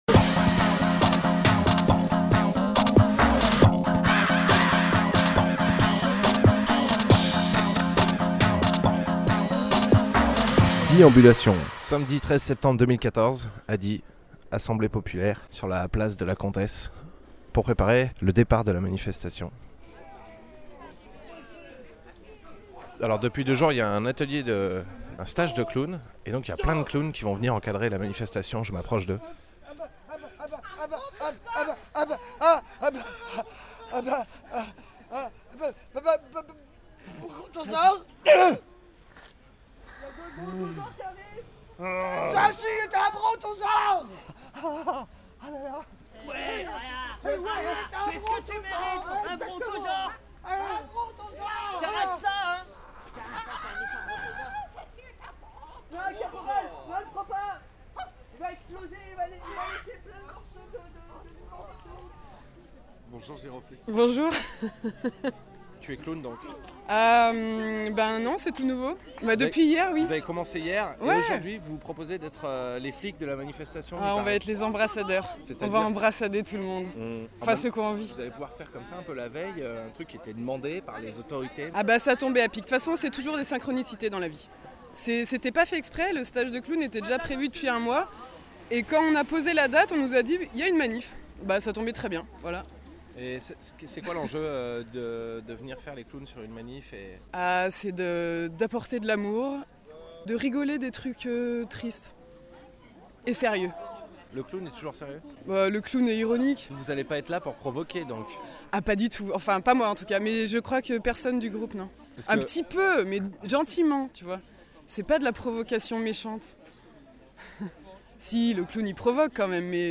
Emission - Reportages 13/09/14 à Die : Manifestation contre les violences policières et pour le partage de l’espace public Publié le 21 septembre 2014 Partager sur… Télécharger en MP3 Un micro dans la manifestation du 13 septembre 2014 à Die, “ contre les violences policière et pour le partage de l’espace public ” c’était le thème.